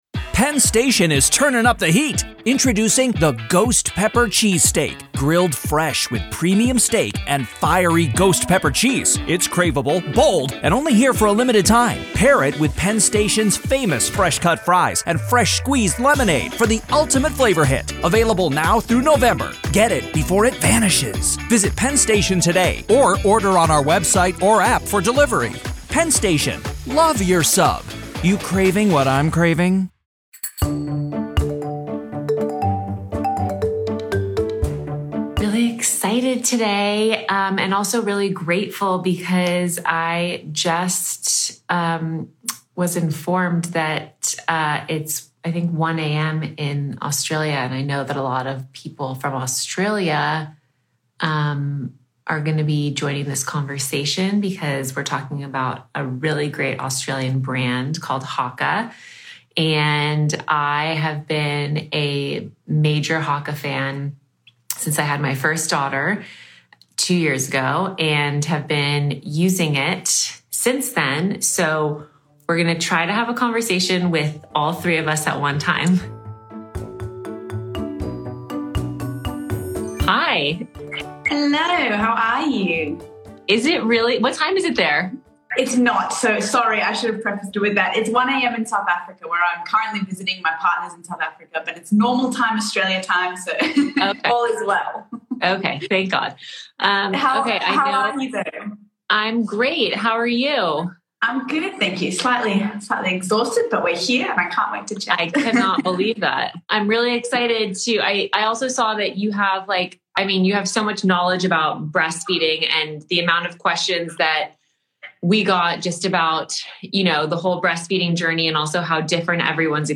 Both women remind us that feeding our babies is a very personal journey. What works for one mom might not work as well for you and your baby.